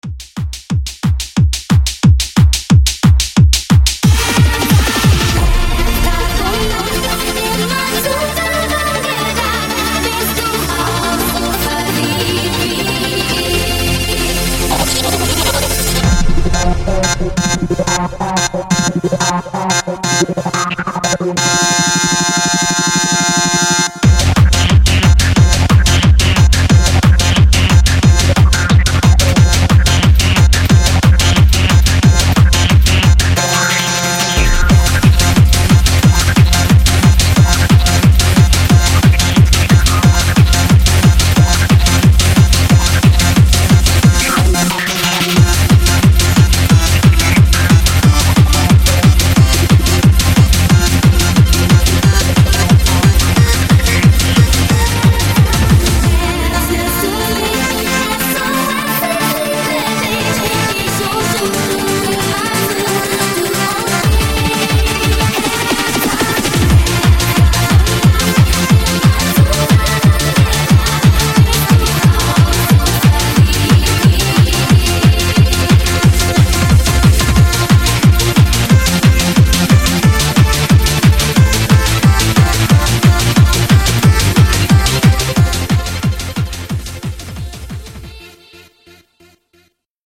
Genre: DANCE
Clean BPM: 128 Time